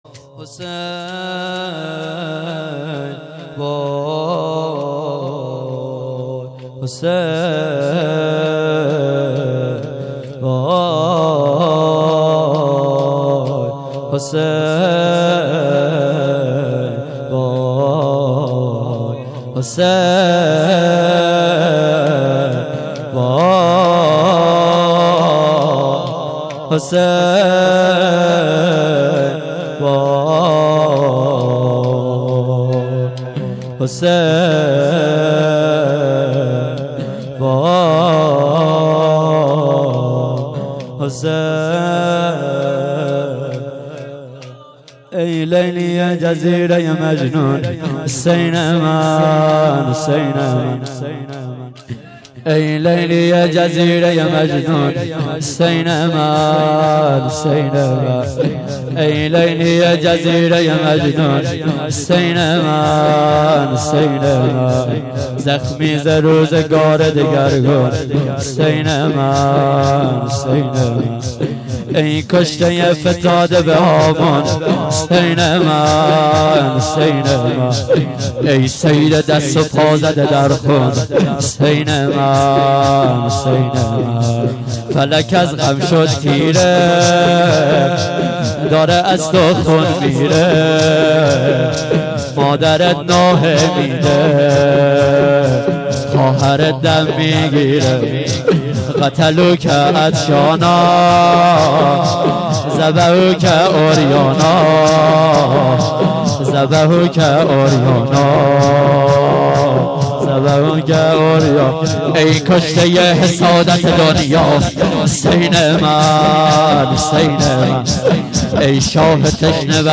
ای لیلی جزیره مجنون (شور)
لطمه-زنی-ای-لیلی-جزیره-ی-مجنون.mp3